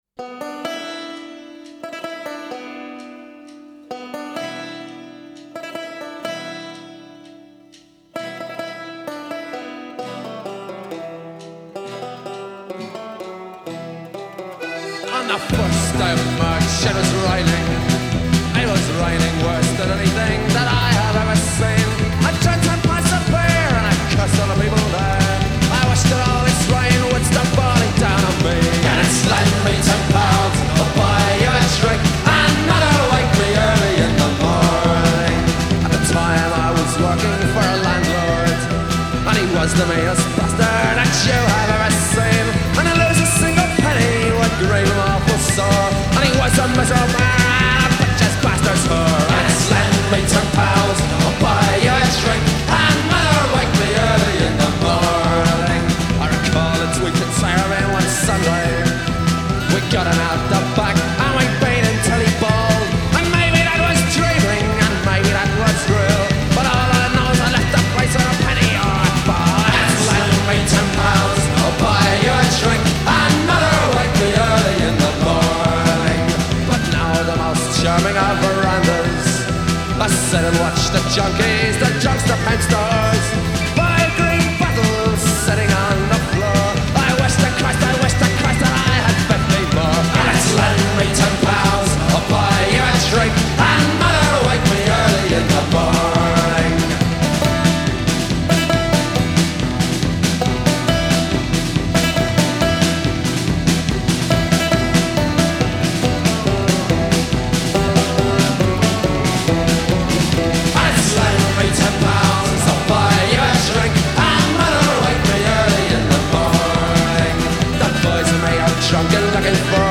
BBC Live 1984-89